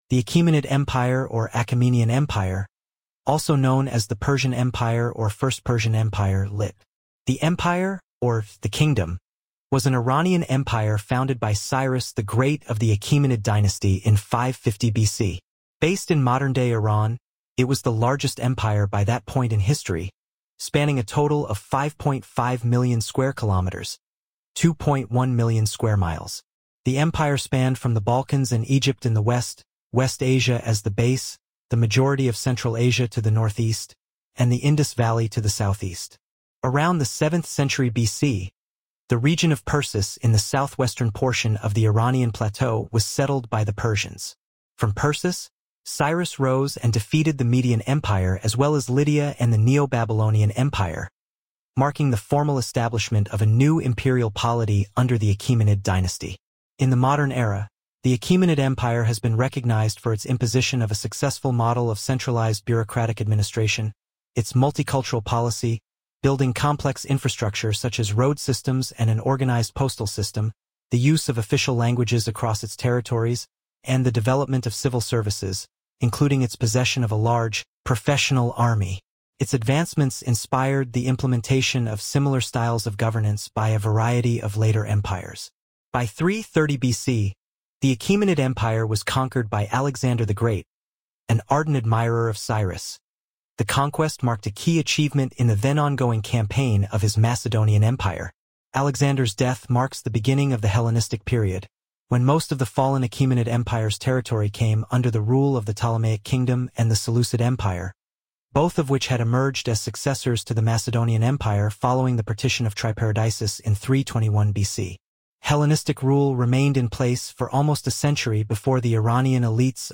ملف:Wikipedia - Achaemenid Empire (spoken by AI voice).mp3 - المعرفة
English: Achaemenid Empire narrated by AI-generated voice using SoniTranslate as described here
voice en-US-BrianMultilingualNeural / en-US-BrianNeural
Wikipedia_-_Achaemenid_Empire_(spoken_by_AI_voice).mp3